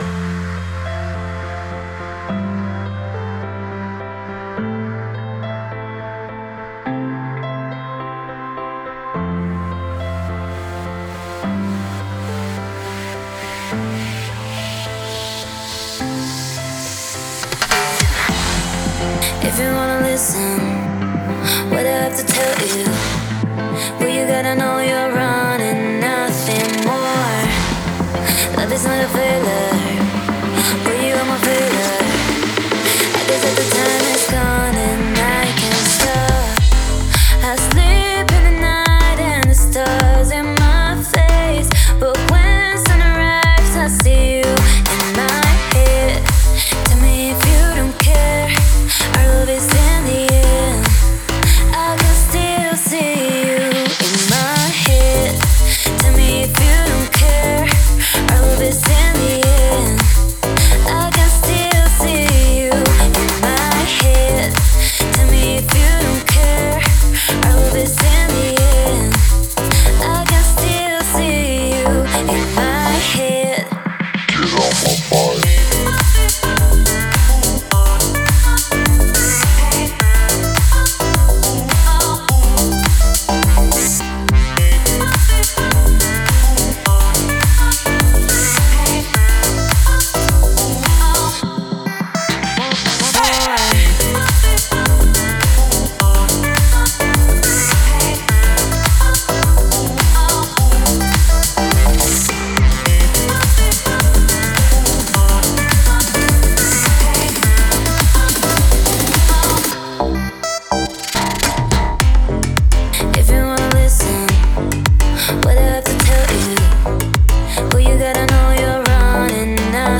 который сочетает элементы поп и электронной музыки.